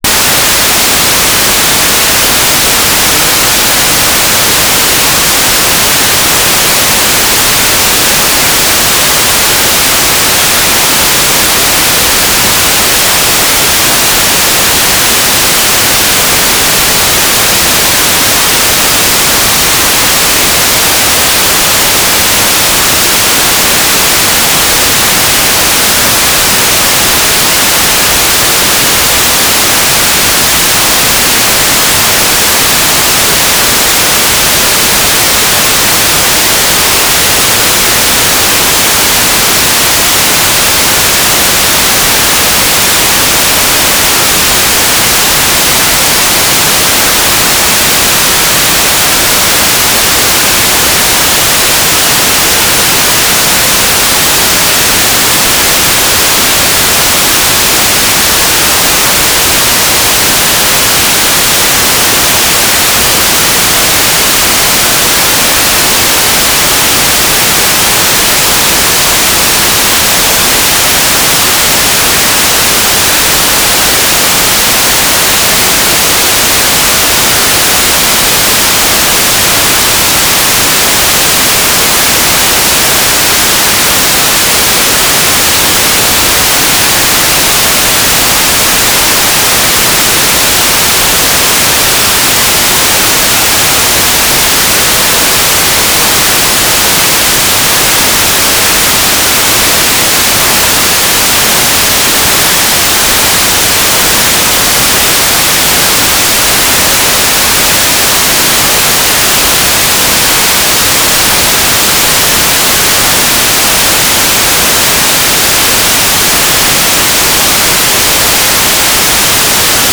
"transmitter_mode": "FSK AX.25 G3RUH",